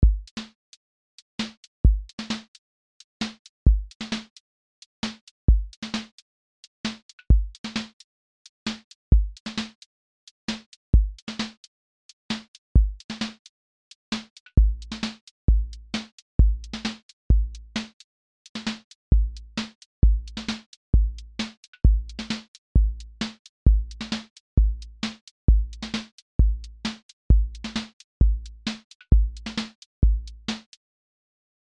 • voice_kick_808
• voice_snare_boom_bap
• voice_hat_trap
• voice_sub_pulse
• tone_warm_body
• fx_drum_bus_glue